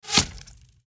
skyrim_bow_hitflesh.mp3